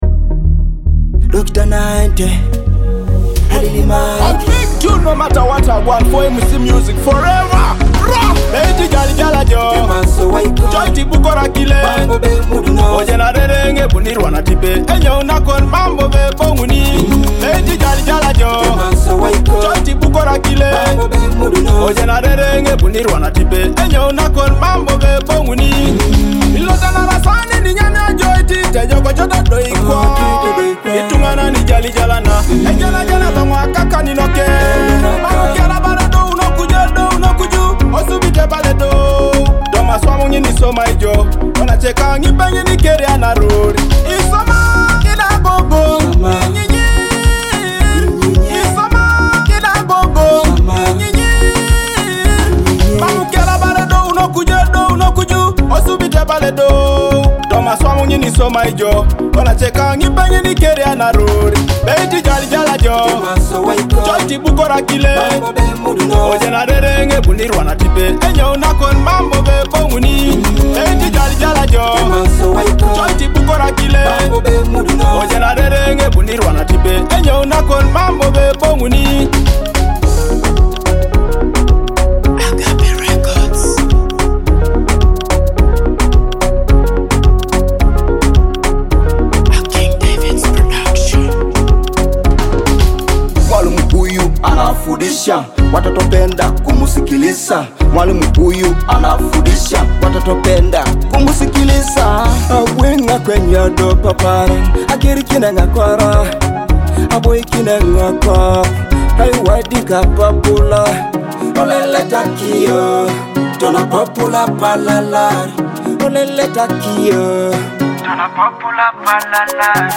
a powerful Teso dancehall track